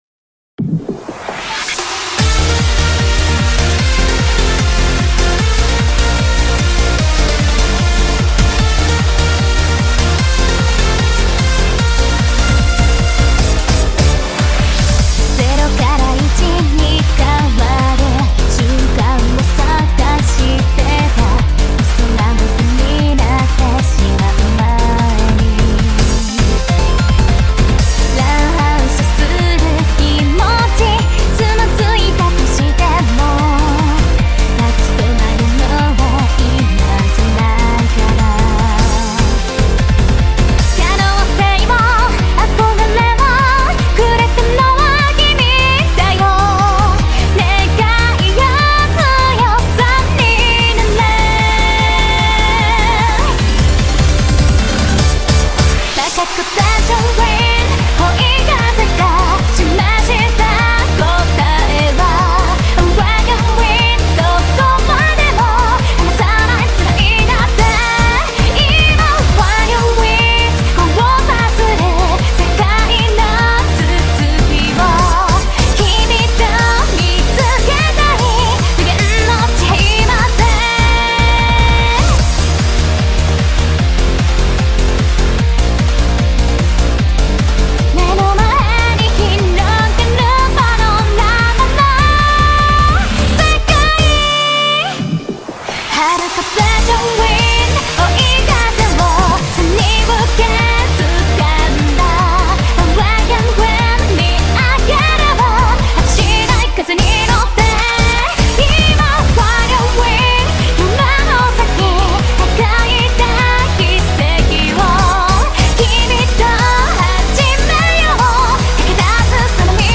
BPM150
Audio QualityPerfect (Low Quality)